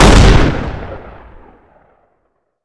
Index of /server/sound/weapons/shotgundoublebarrel
wpn_dblbarrelshotgun.wav